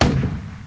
机炮.ogg